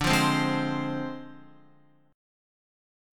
Dm7b5 chord